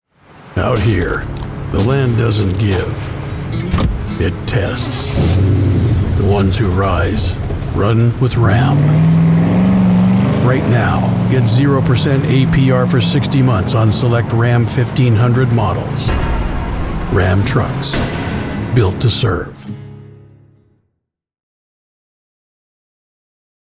Gritty, Rugged, American Voiceover
• Gritty, masculine tone with Western authenticity
• Cinematic pacing perfect for national TV and radio
Truck Commercial Voiceover Demos
• Fully treated booth